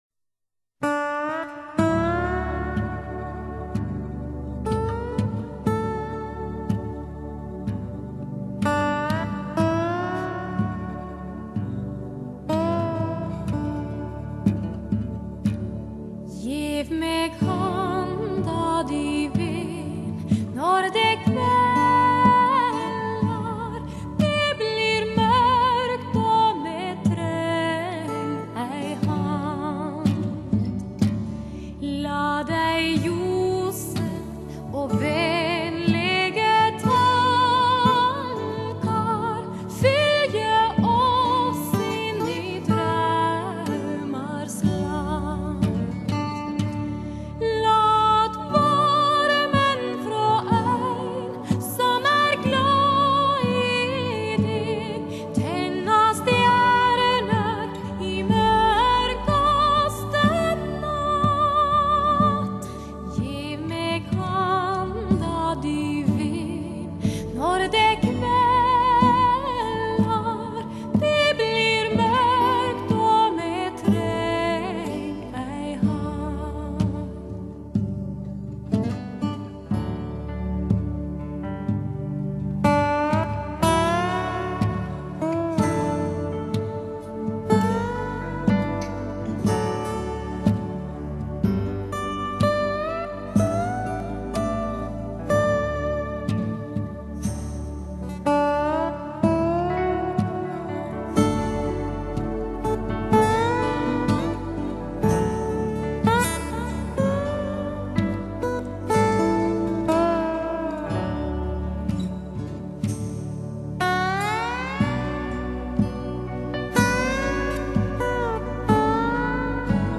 天籟 夜鶯美聲
天籟美聲多如流水，但要找一個系列錄音極佳、音樂性豐富、活生感一流，讓你印象深刻的唱片卻很困難。